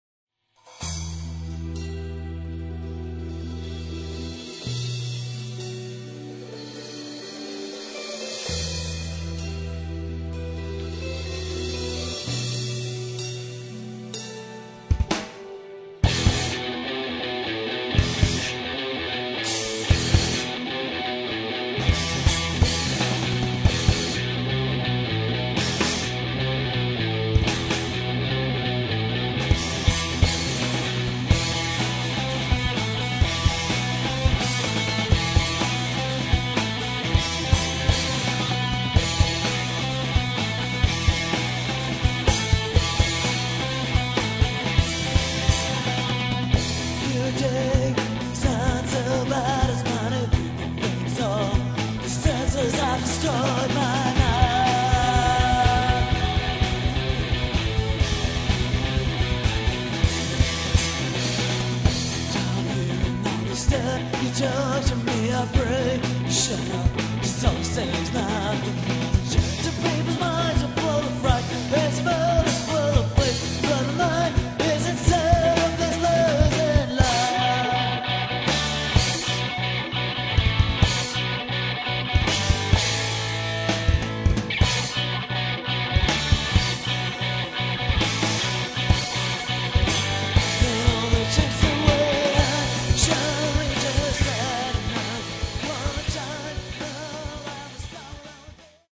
Hardrockcombo
Gitarre, Gesang
Drums